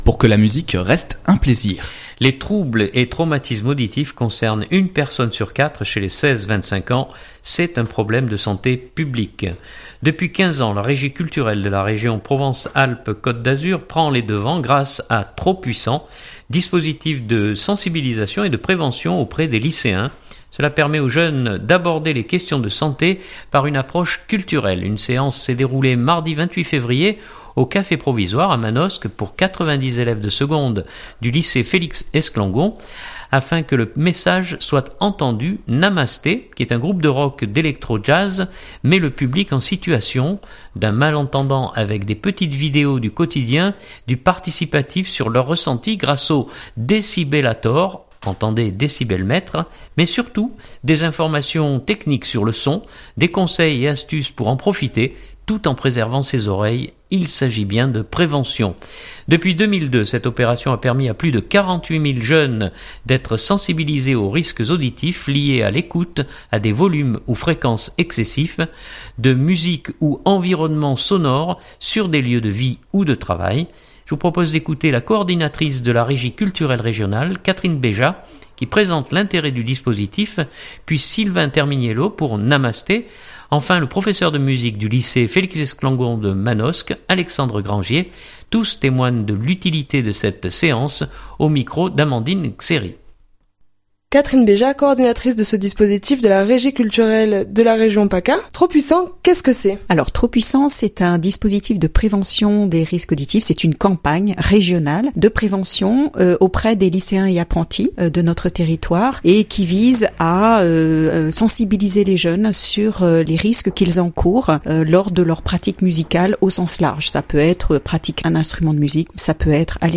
Journal du 2017-03-01 Trop Puissant.mp3 (5.87 Mo)